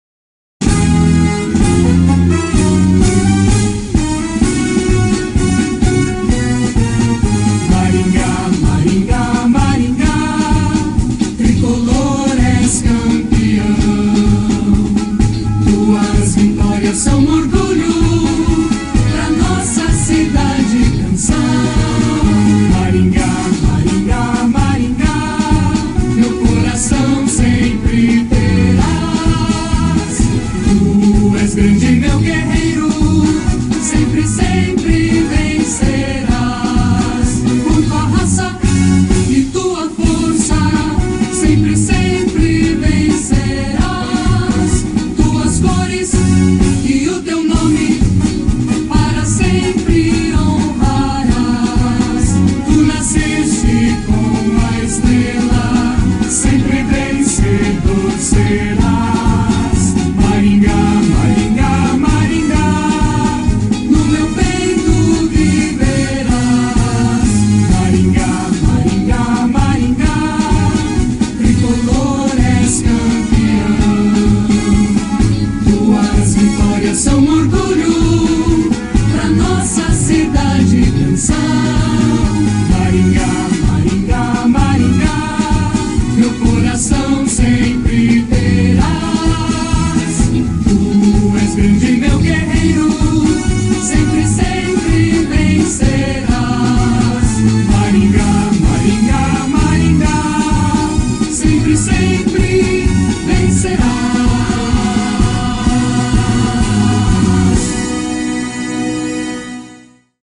Hino Oficial